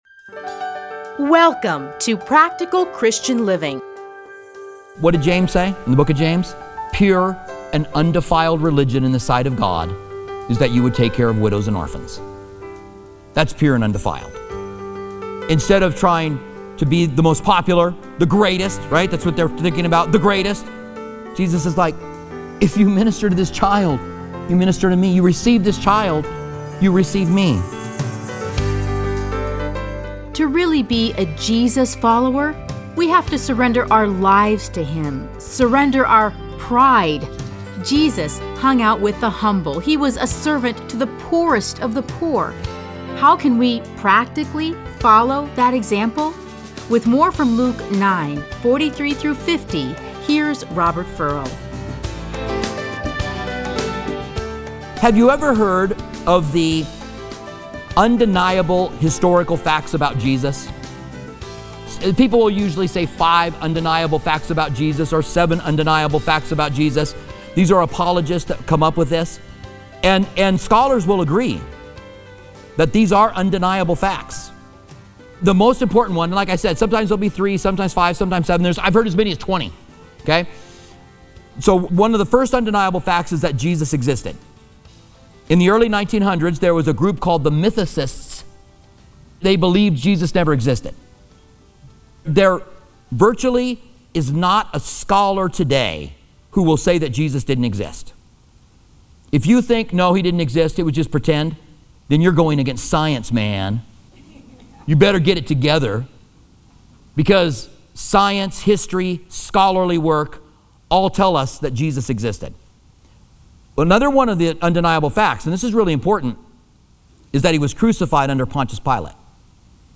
Listen to a teaching from Luke 9:34-50.